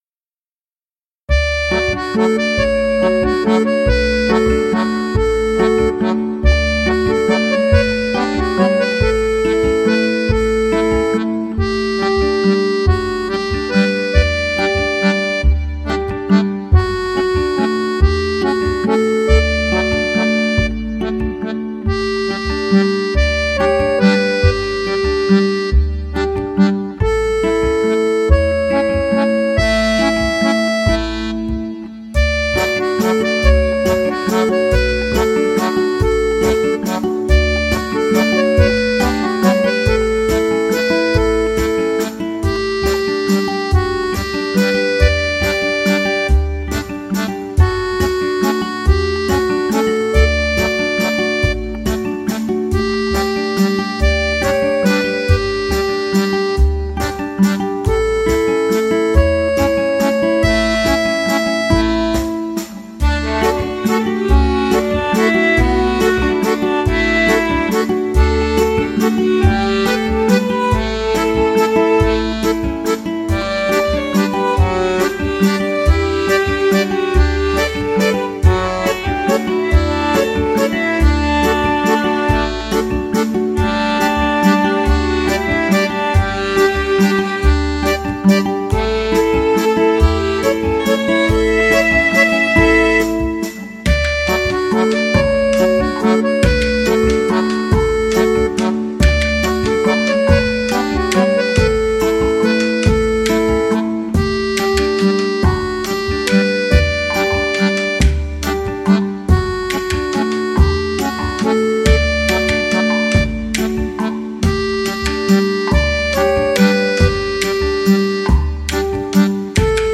Французская традиционная музыка для души без слов